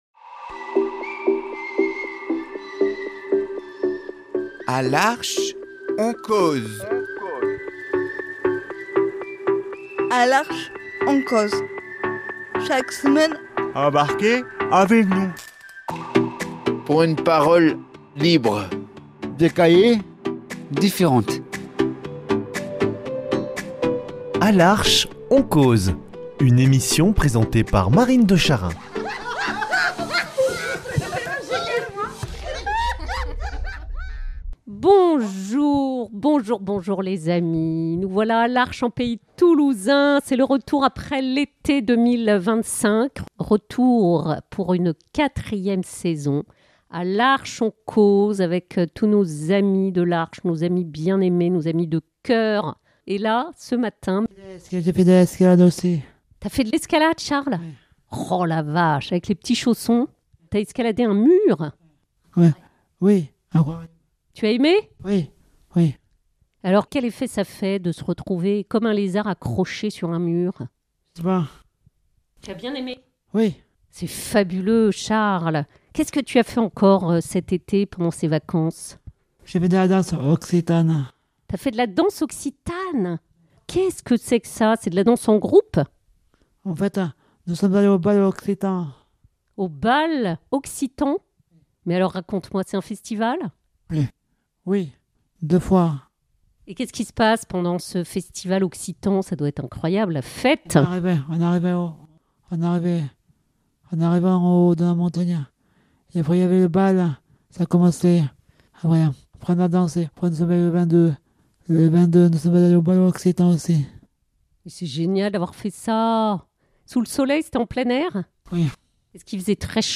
lundi 6 octobre 2025 A l’Arche, on cause Durée 12 min